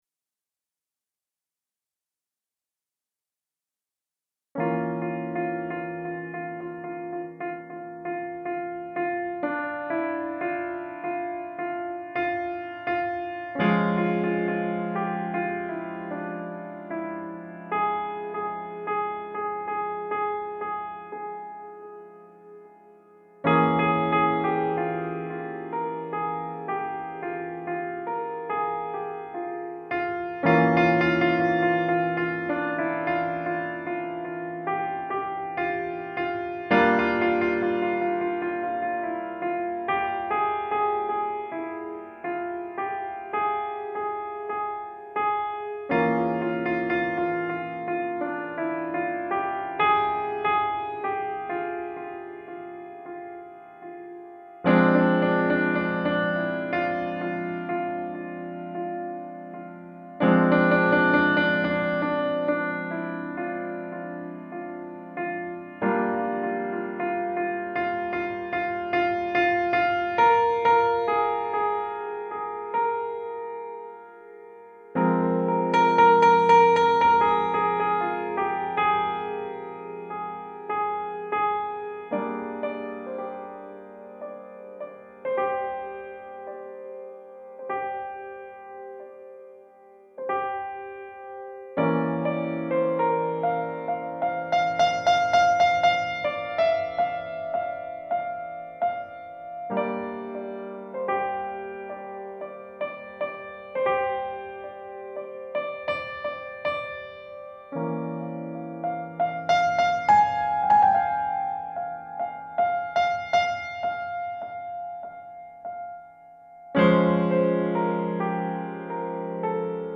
Some piano excercises and improvisations 27mzo Click here to watch the video on youtube
Improvisation for piano based on a chord